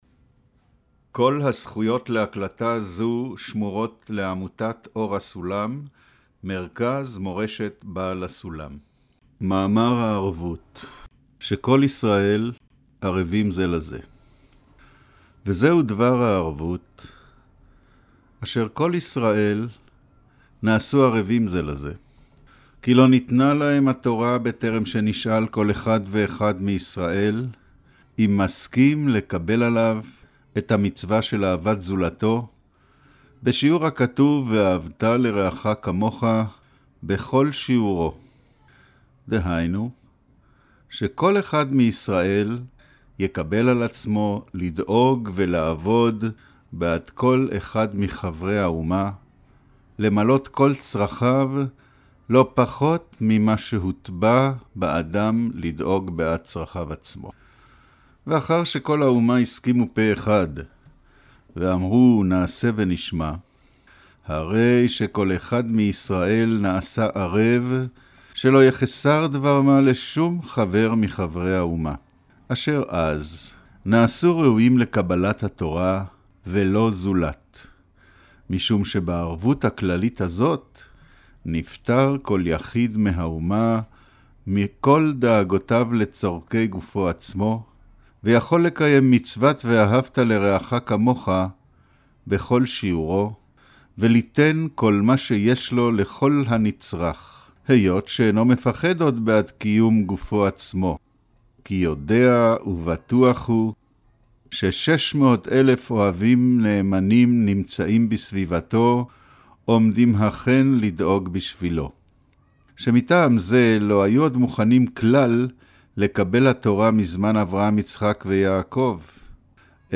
שיעור מבעל הסולם הערבות (המשך ממאמר מתן תורה)